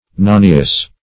Search Result for " nonius" : The Collaborative International Dictionary of English v.0.48: Nonius \No"ni*us\, n. [Latinized form of Nunez, the name of a Portuguese mathematician.]